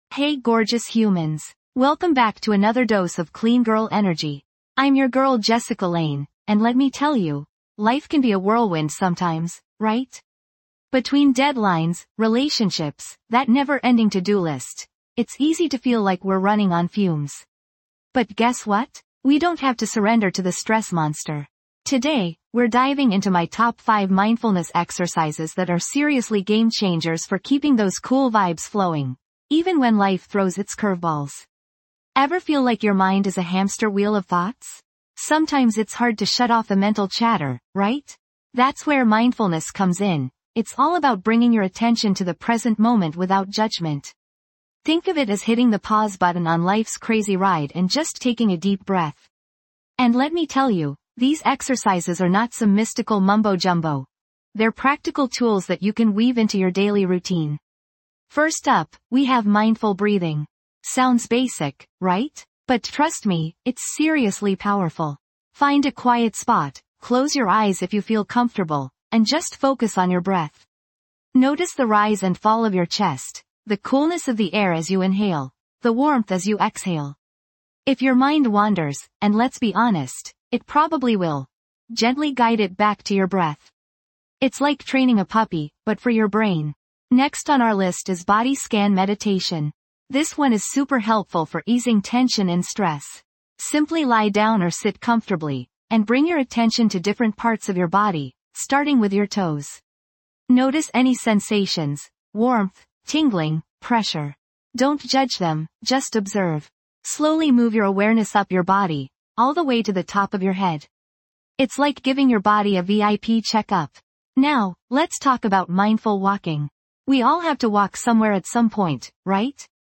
Key Takeaways:. Learn practical mindfulness techniques for stress relief Understand the benefits of mindfulness for mental well-being Experience guided mindfulness exercises.
This podcast is created with the help of advanced AI to deliver thoughtful affirmations and positive messages just for you.